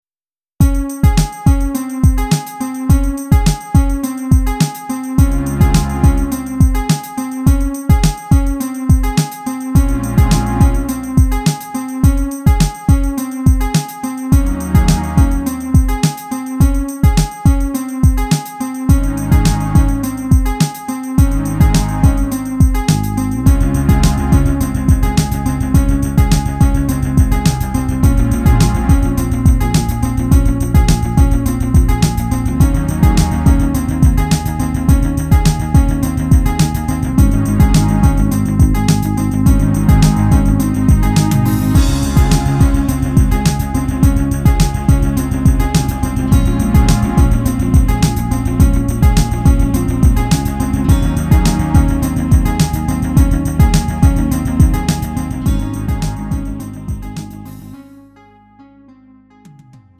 음정 -1키 3:29
장르 구분 Lite MR